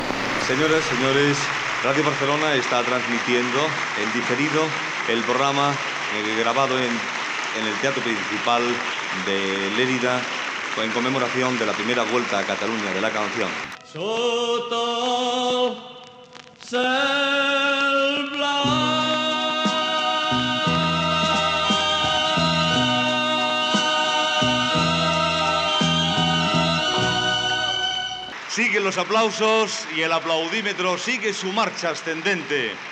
Transmissió enregistrada el 19 d'abril del 1966 al Teatro Principal de Lleida.
aplaudiments del públic
Musical